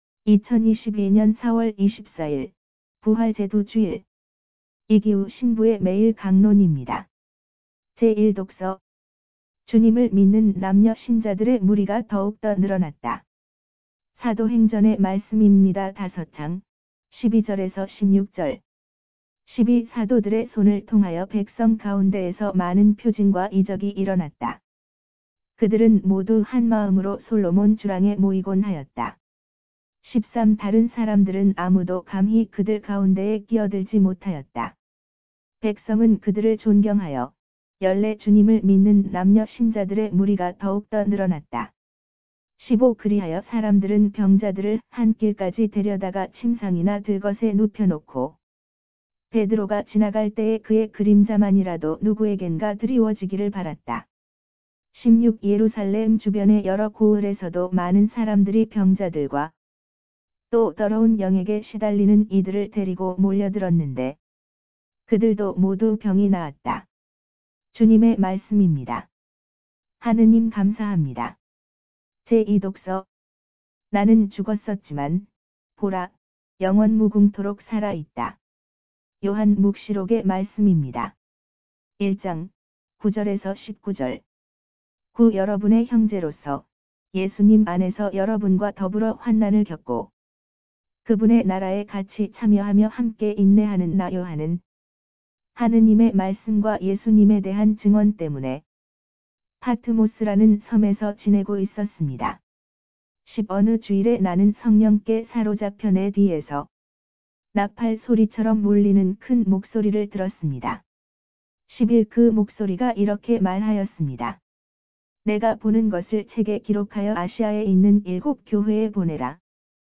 기타 강론 묵상